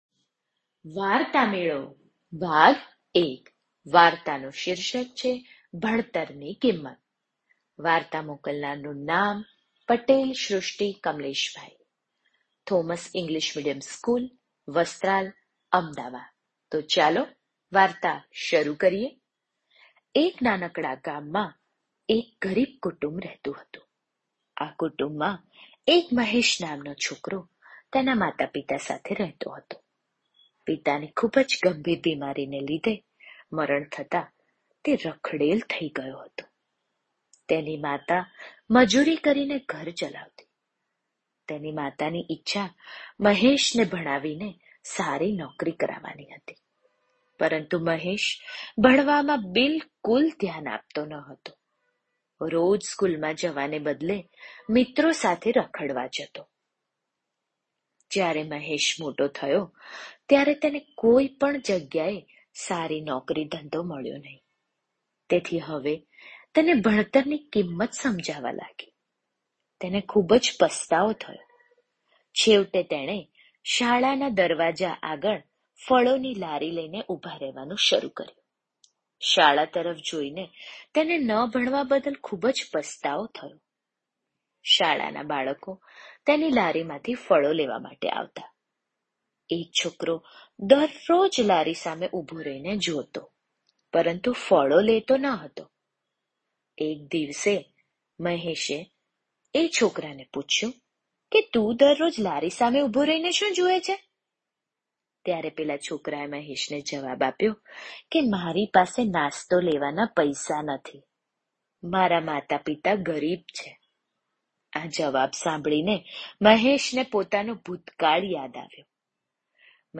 ભણતર ની કિંમત - ઓડિયો વાર્તા - વાર્તામેળો